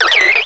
cry_not_cherubi.aif